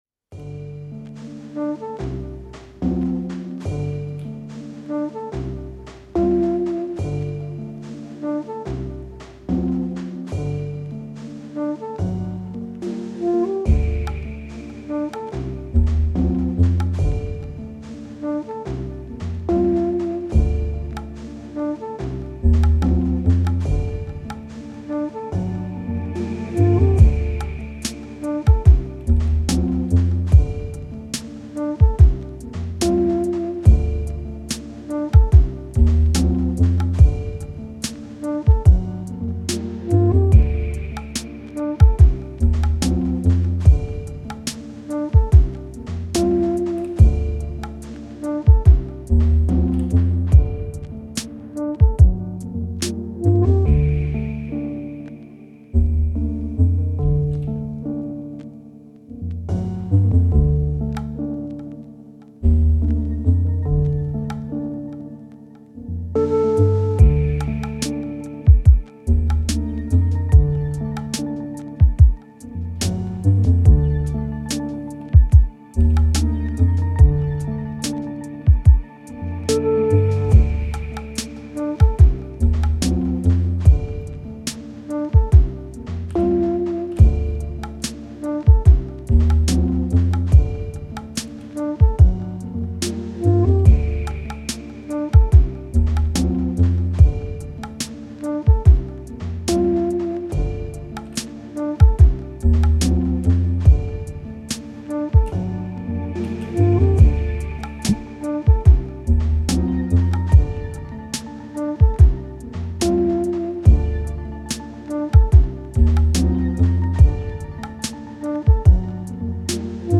I’m drawn to trip hop and minimal stuff but I can’t stop sampling jazz and I’m addicted to boom bap drums.